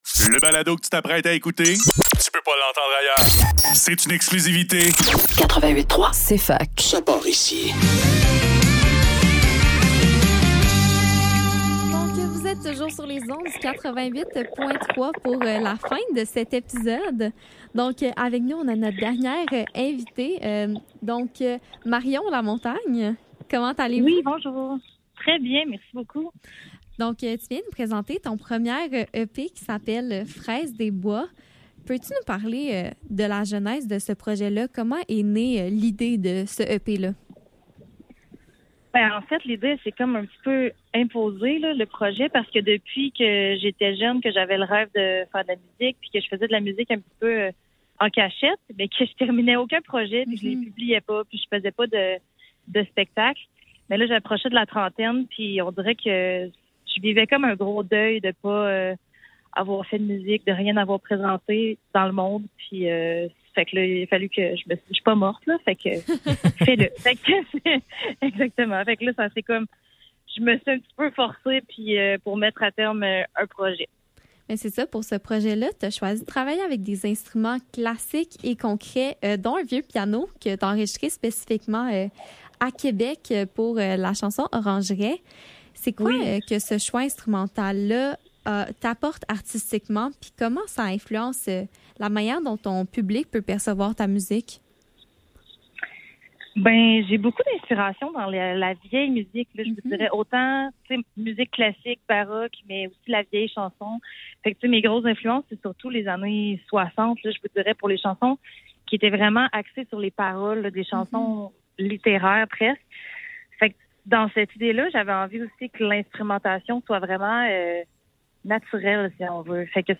Cfaktuel - Entrevue